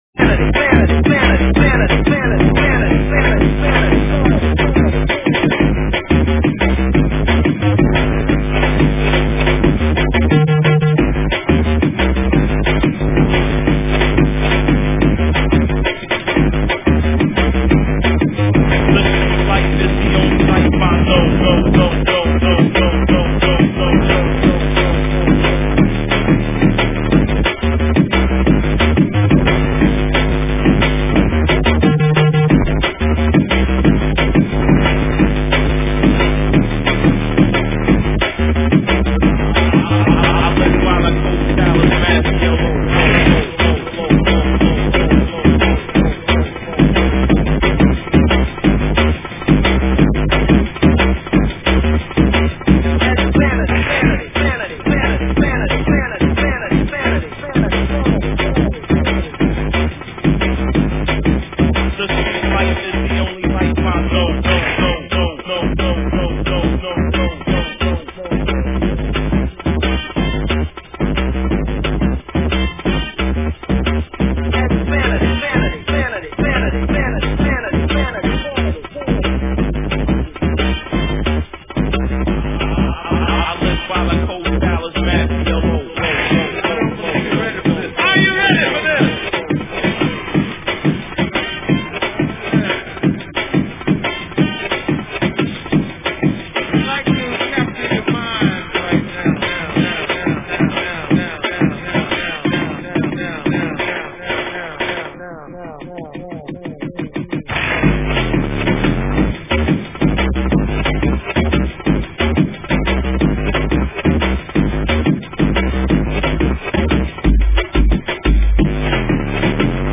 Drum&Bass, Dubstep